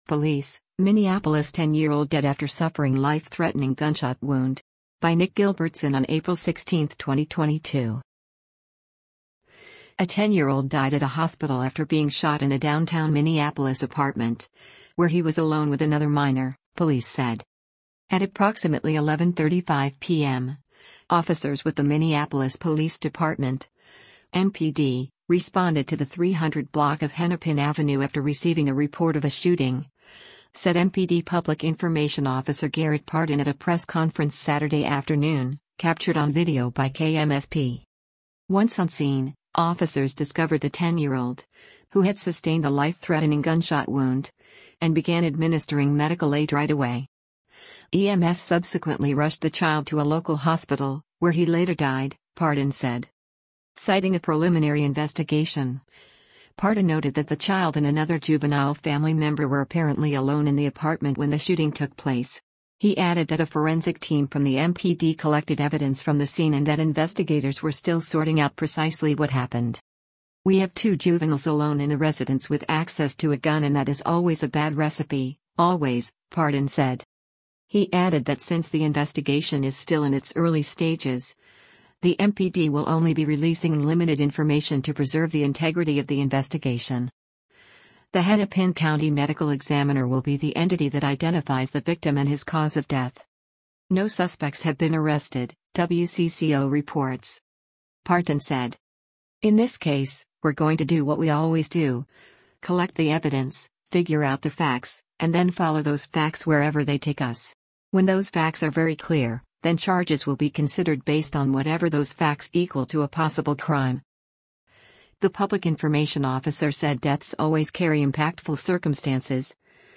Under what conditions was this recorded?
at a press conference Saturday afternoon